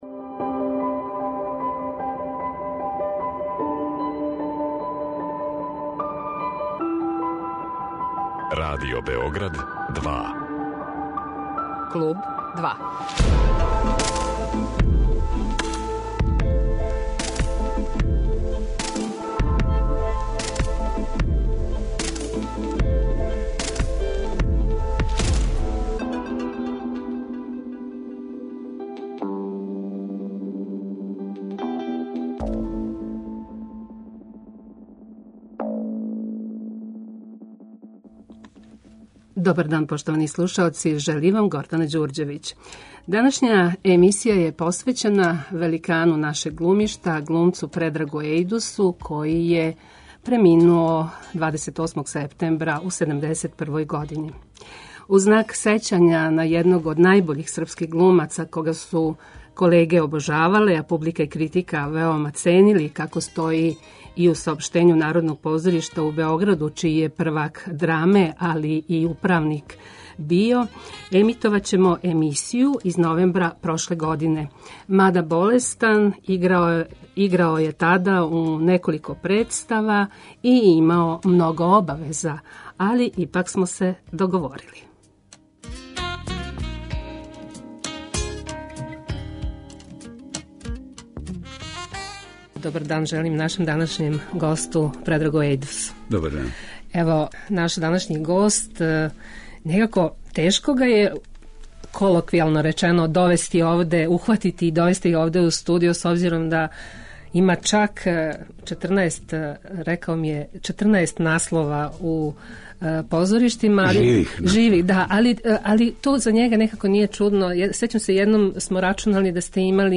разговарала је сa Предрагом Ејдусом у новембру прошле године